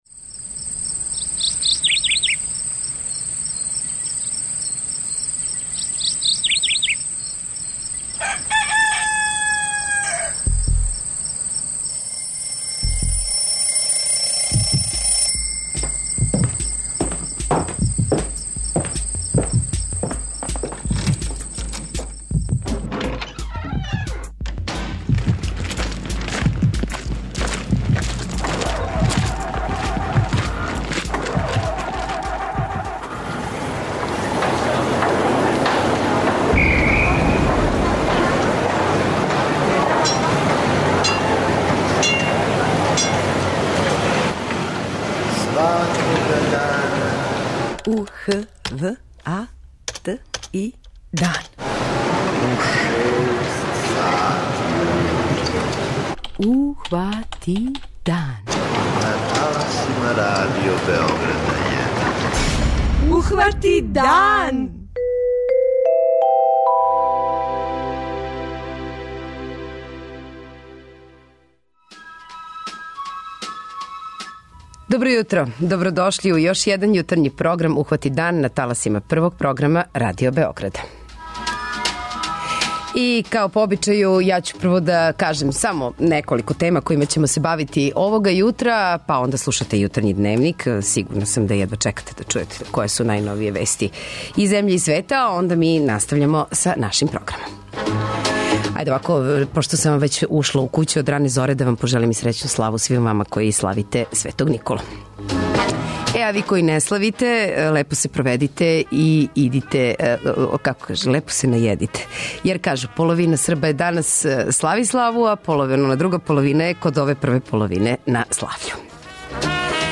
Наш репортер ће нам се јавити са београдске пијаце Зелени венац, где ће проверити цене намирница које се уобичајено спремају на овај дан.